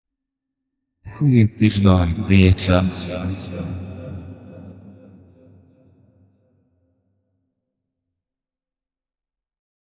Free AI Sound Effect Generator
Beautiful many-faced antichrist with a soothing voice speaking tongues, reverb, heavenly ambience